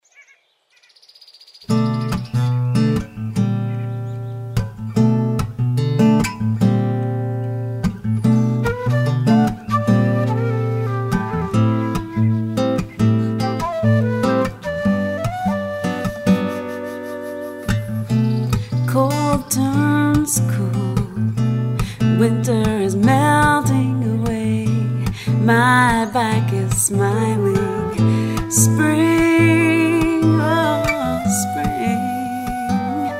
fun and quirky songs